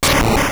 destroy.wav